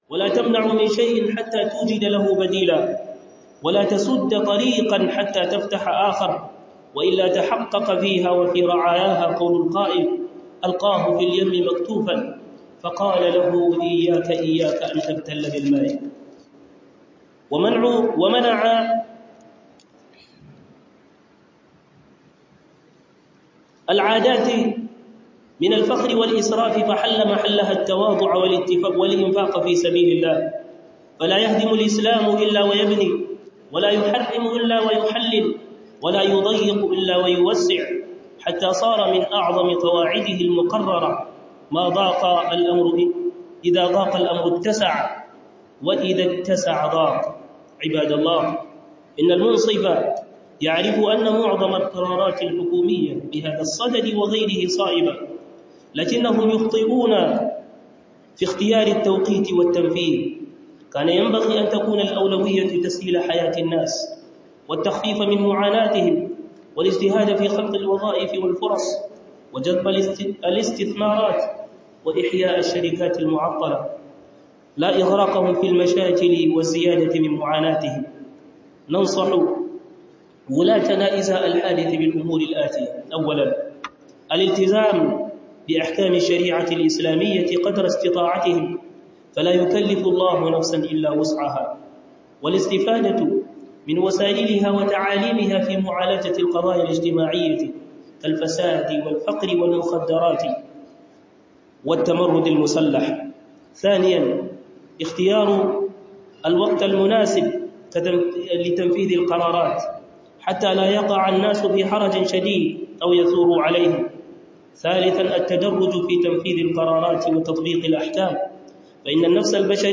- Huduba